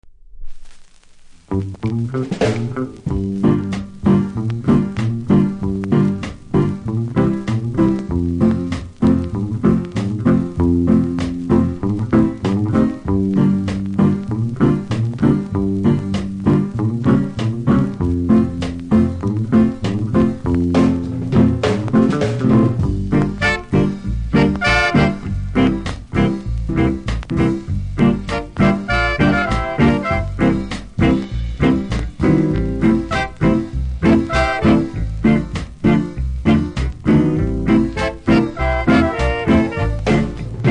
キズにより前半1週に1回ノイズ拾いますので試聴で確認下さい。